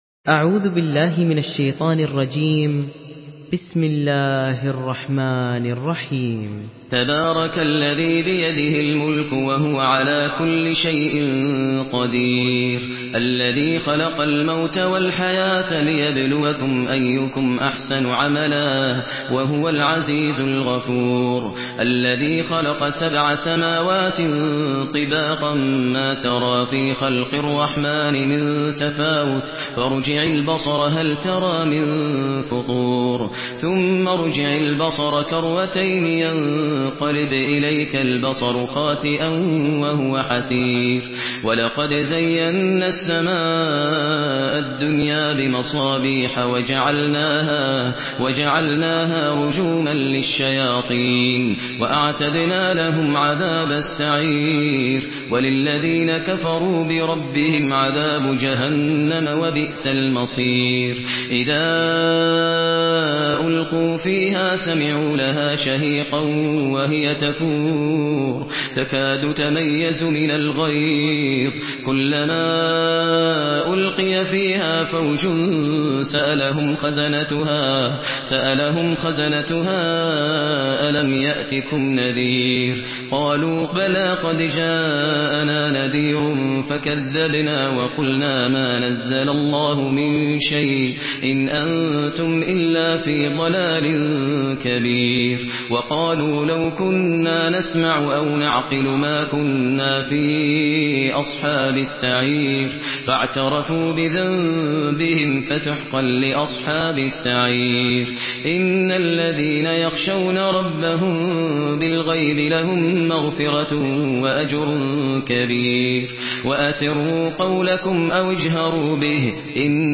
Surah Mülk MP3 by Maher Al Muaiqly in Hafs An Asim narration.